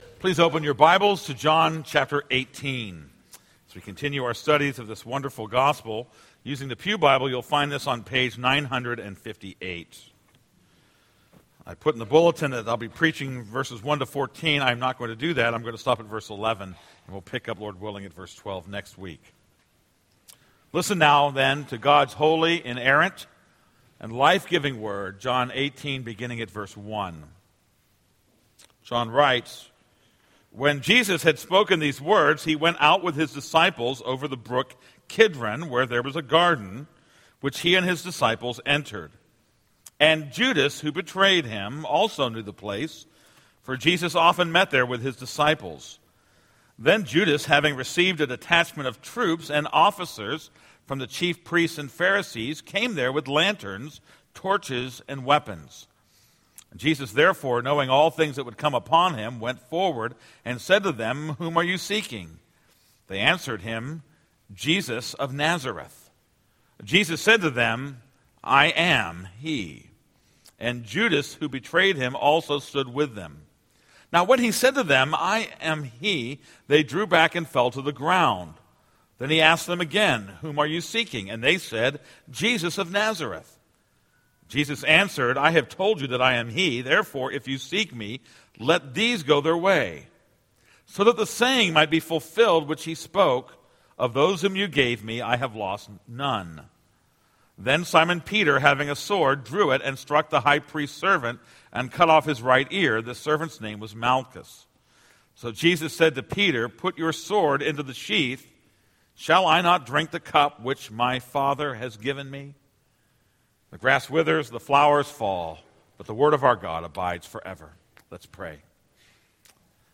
This is a sermon on John 18:1-11.